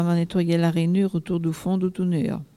Bois-de-Céné
collecte de locutions vernaculaires
Catégorie Locution